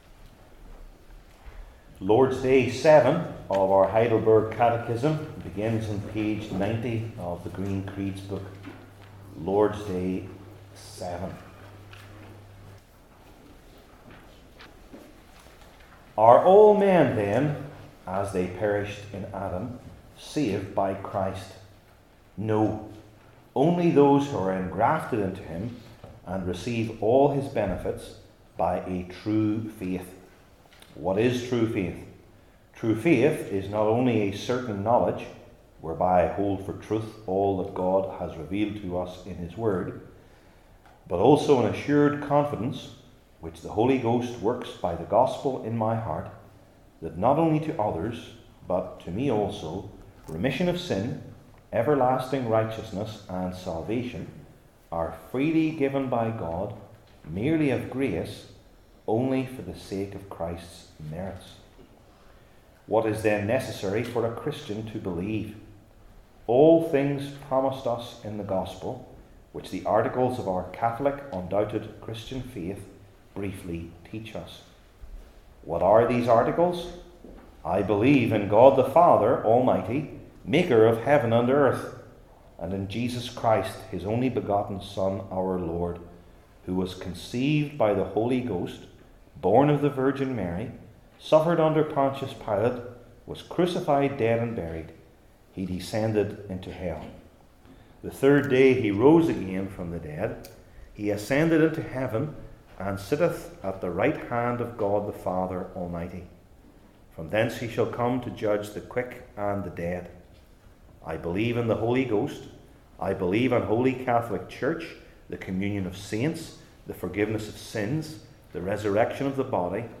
Heidelberg Catechism Sermons I. The Answer to This Question II.